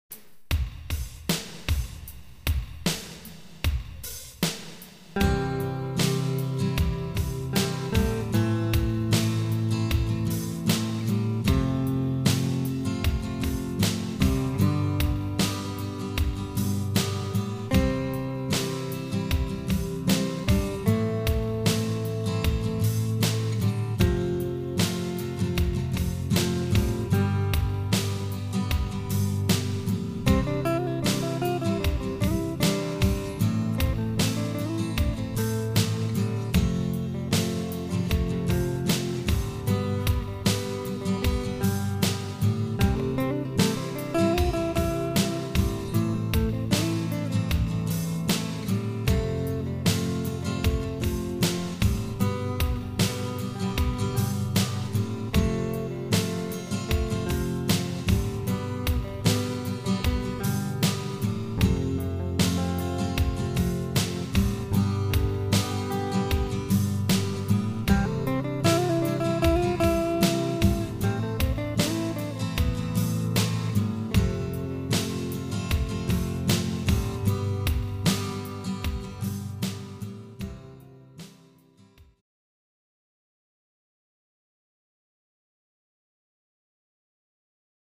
Listen to this progression using the chords C Am F and G. Play a few notes using the scale below over it and see what you can come up with.
I've played a slow easy lead over this
progression using the C major scale above the Am pentatonic scale.